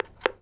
phone_hangup_dial_02.wav